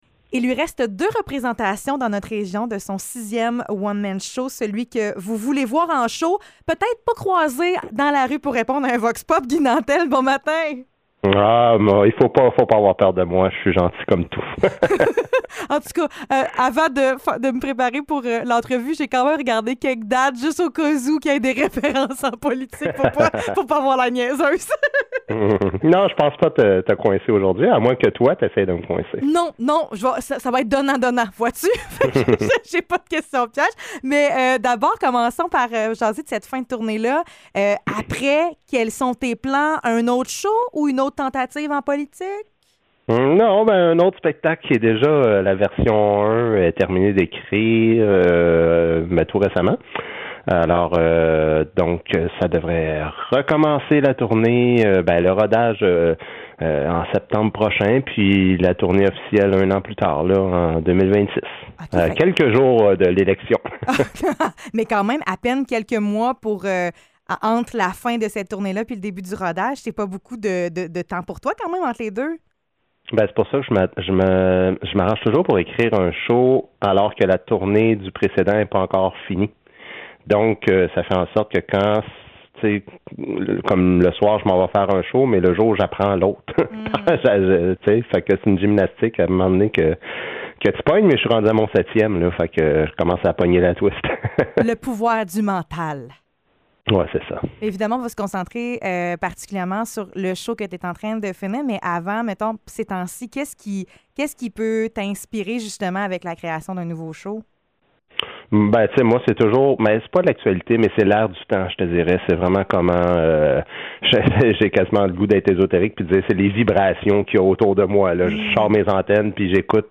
Entrevue avec Guy Nantel